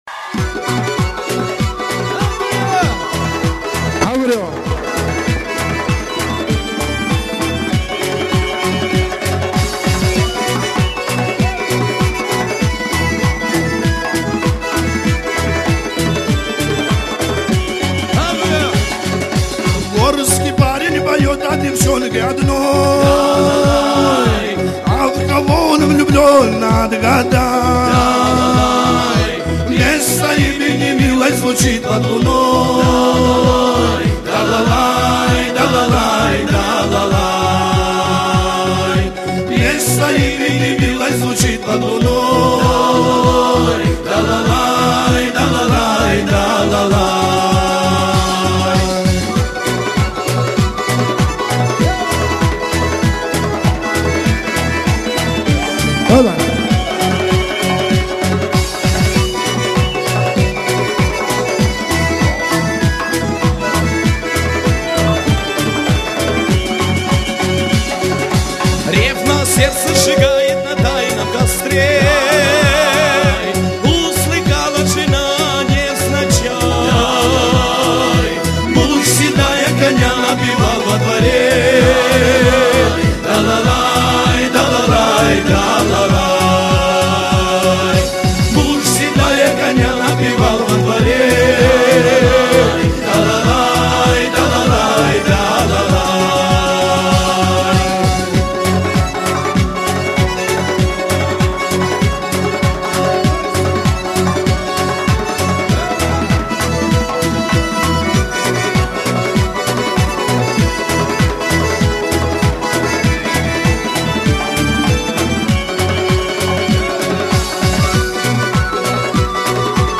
Адыгская музыка